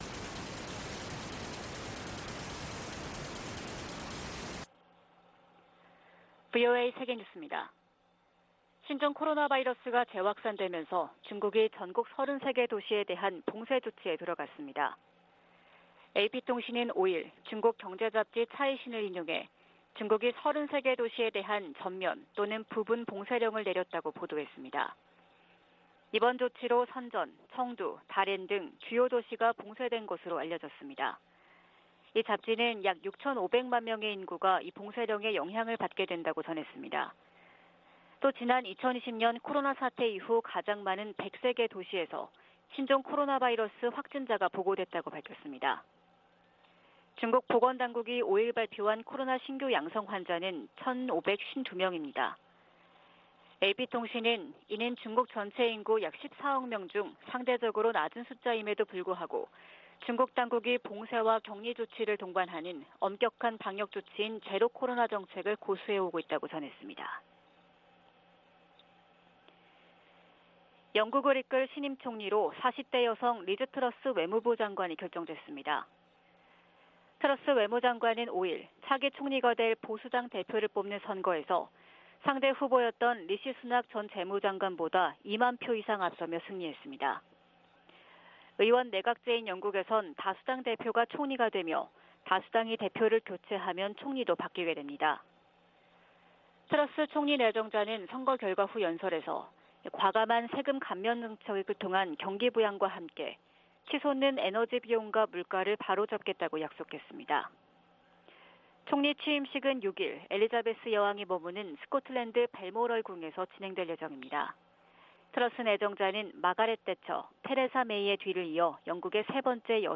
VOA 한국어 '출발 뉴스 쇼', 2022년 9월 6일 방송입니다. 미국과 한국, 일본 북핵 수석대표가 일본에서 회동하고 북한 비핵화 문제 등을 논의합니다.